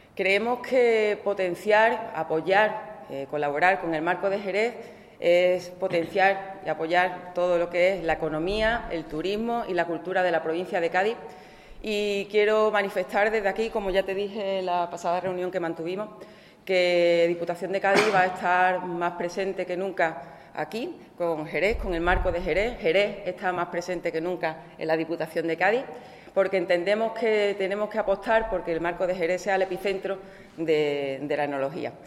La presidenta Almudena Martínez ha participado en la presentación
Corte de Almudena Martínez